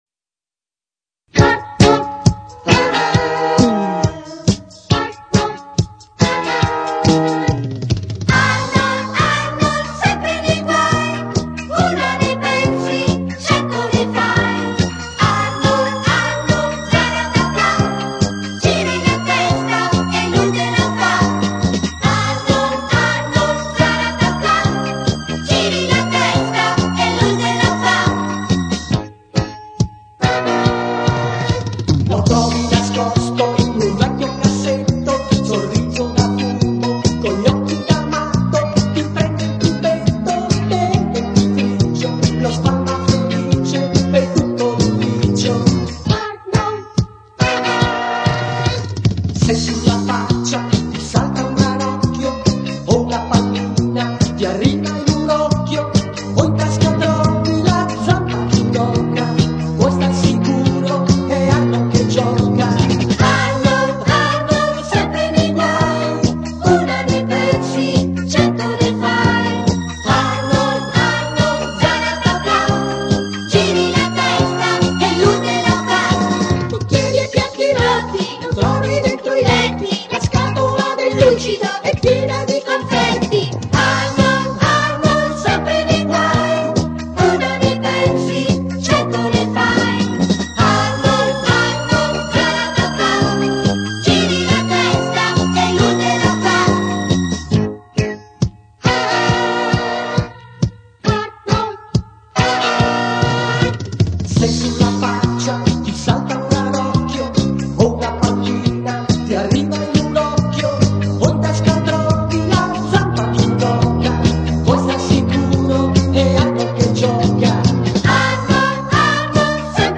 sigla del telefilm